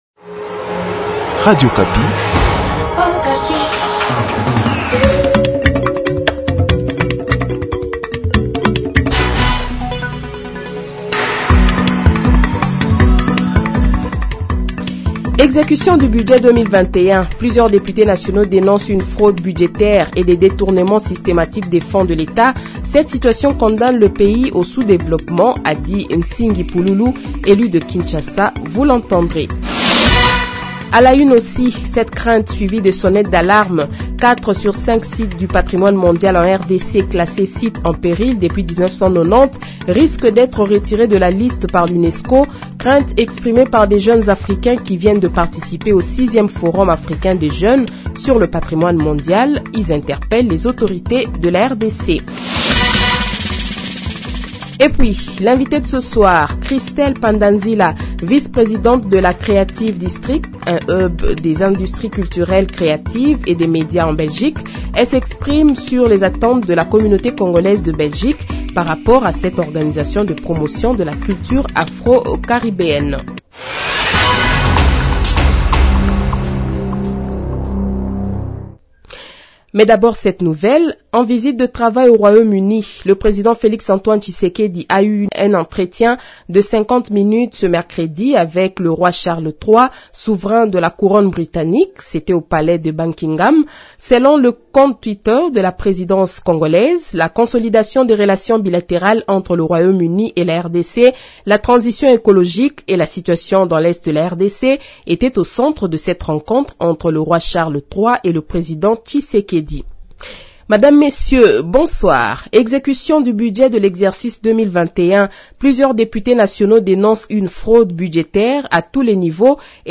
Journal Soir
Le Journal de 18h, 19 Octobre 2022 :